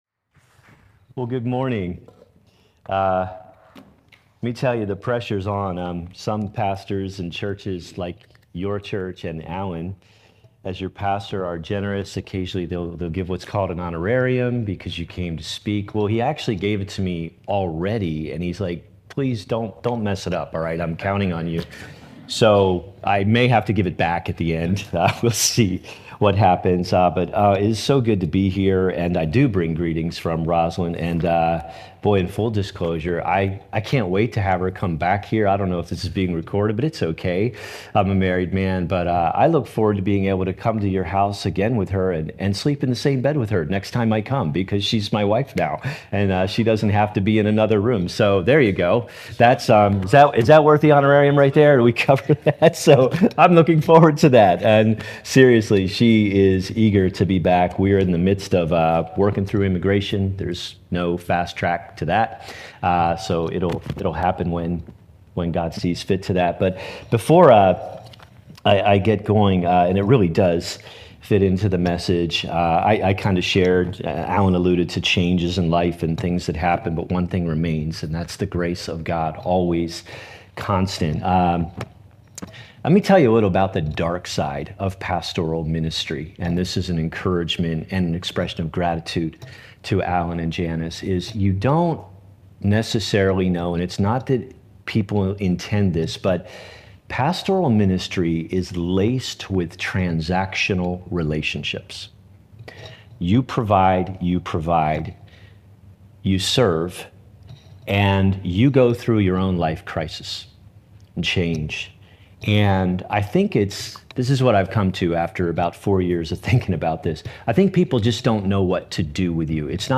Religion Grace Community Sermon Series Christianity Grace Community Church Painted Post Content provided by Sermon Series, Grace Community Church: Painted Post, and NY.